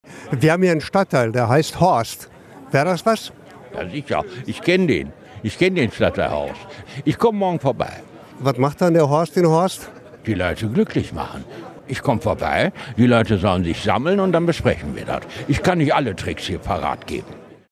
Filmpremiere in Essen - Horst Schlämmer in der Lichtburg